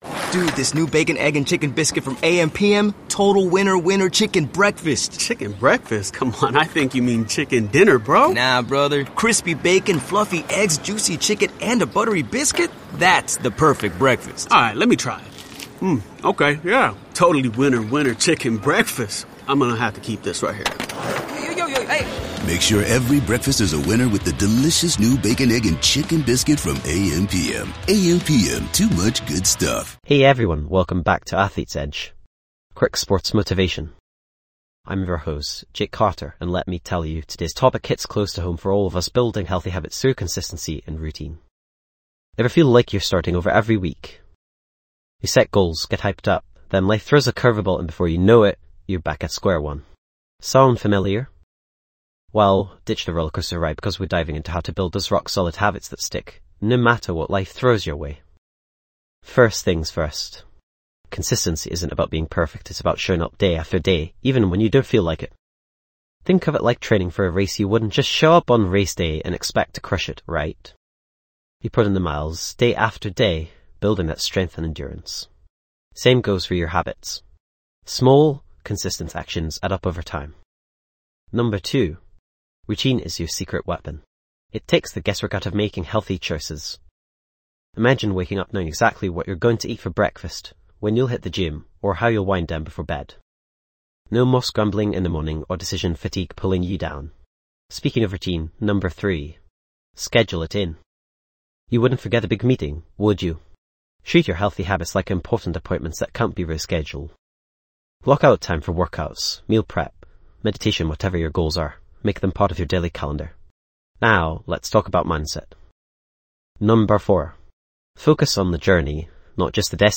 Podcast Category:. Sports & Recreation Motivational Talks Athletic Performance